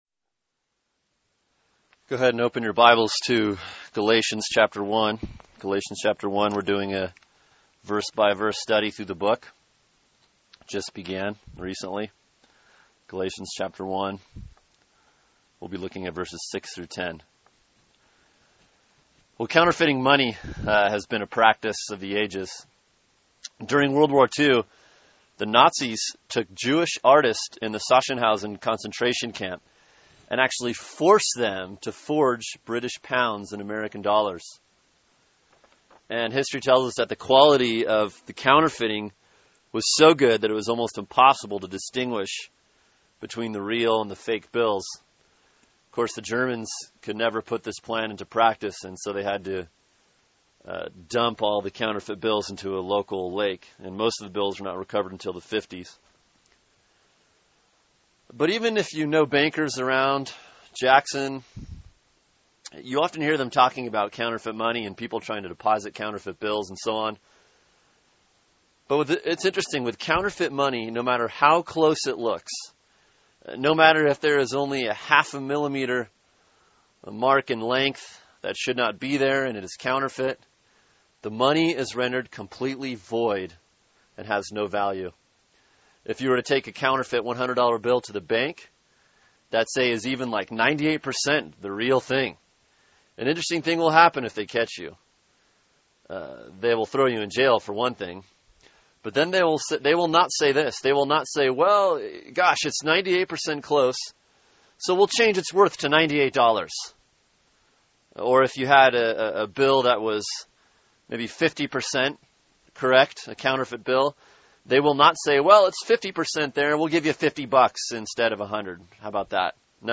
Sermon: Galatians 1:6-10 “No Other Gospel” | Cornerstone Church - Jackson Hole